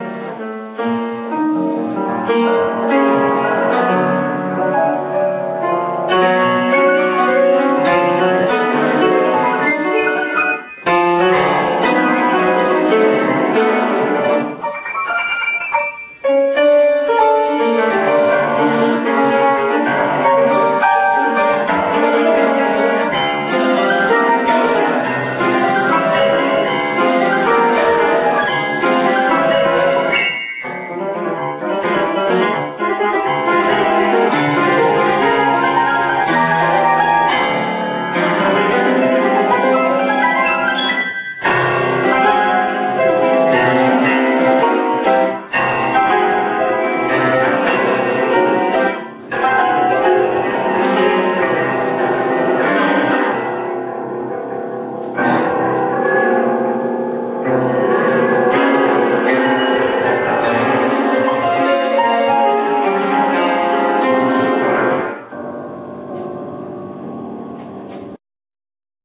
im Hellenikon Idyllion,